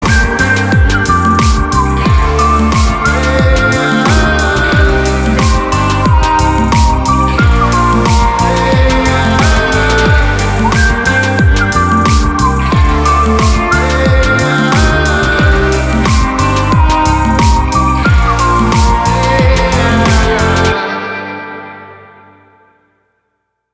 • Качество: 320, Stereo
позитивные
мужской голос
Whistling
мелодичный свист